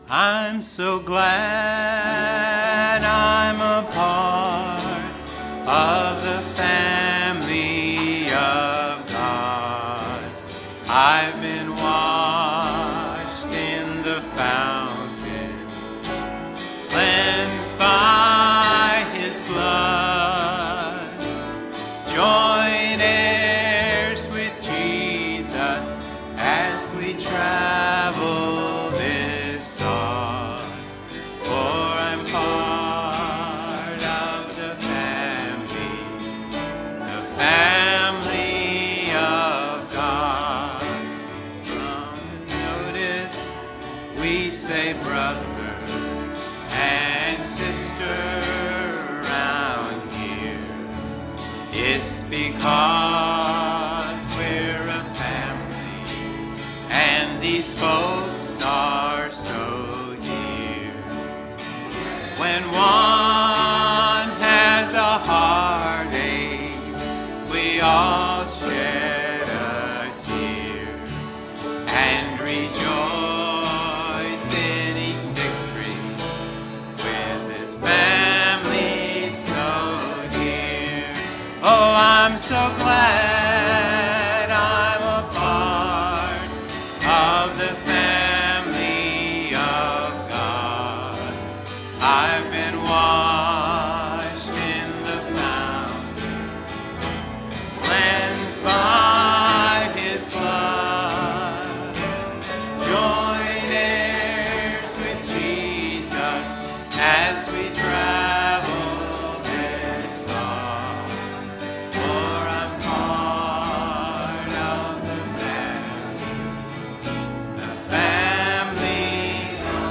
Bowens Mill Conv Apr 2026